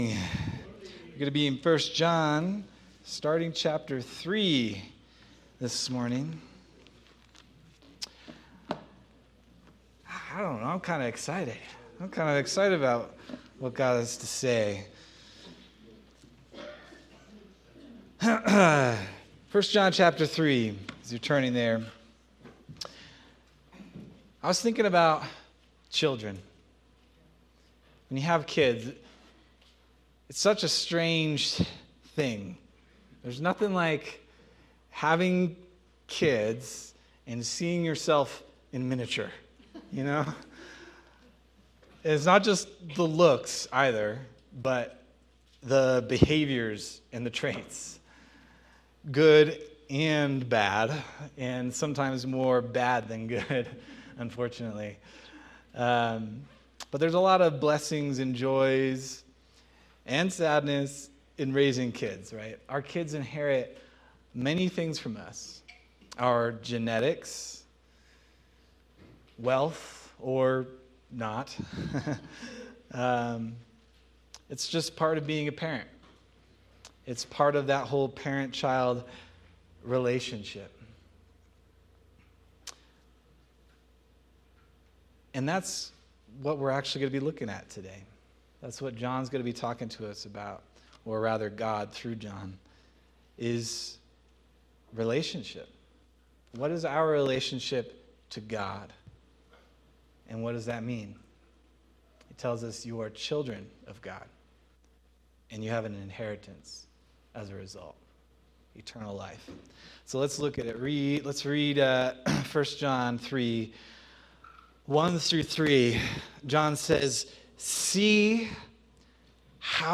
March 22nd, 2026 Sermon